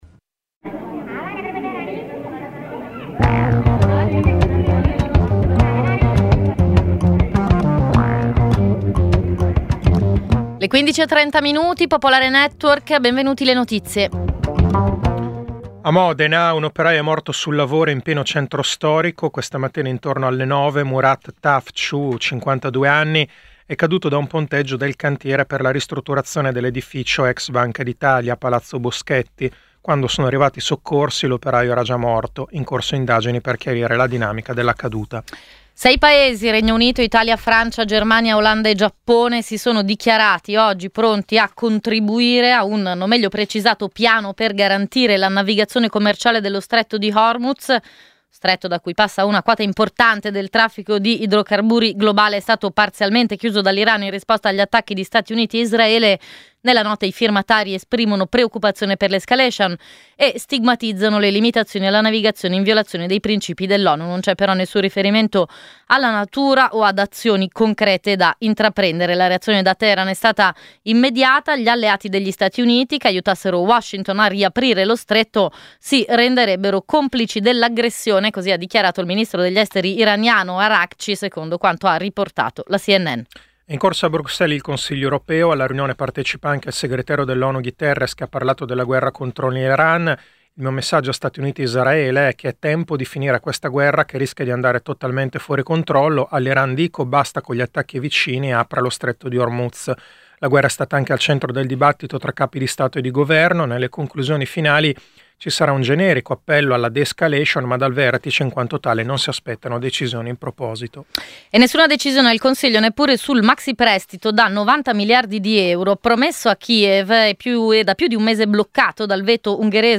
Edizione breve del notiziario di Radio Popolare. Le notizie. I protagonisti. Le opinioni. Le analisi.